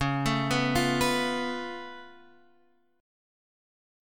Db+7 chord